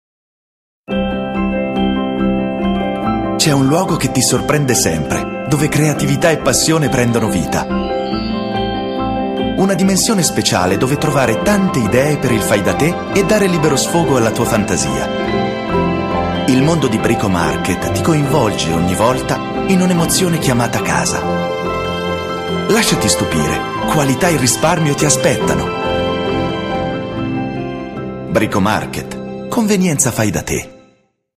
Italian voice over artist Sprecher italienisch Caratteristiche: Voce 20-40 anni TV Radio commercials, documentaries, tutorials, industrial voice over videos
Sprechprobe: Werbung (Muttersprache):
Warm, strong, clear, light, stylish...my versatile voice is avalaible for you now.